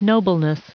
Prononciation du mot nobleness en anglais (fichier audio)
Prononciation du mot : nobleness